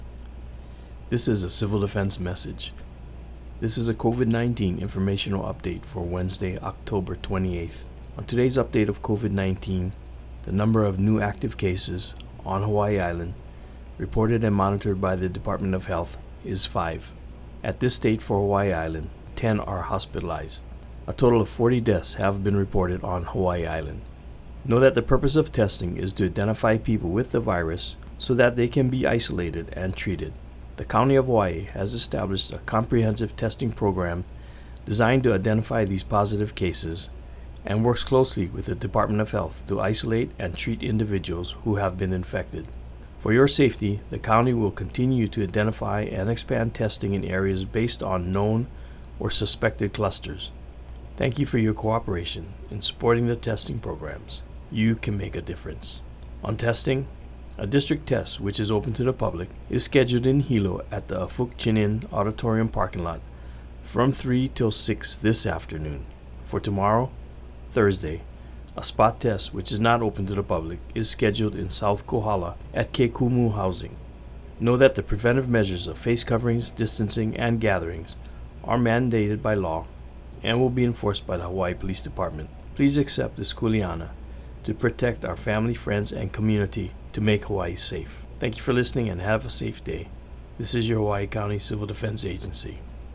From the Hawaiʻi County Civil Defense radio message: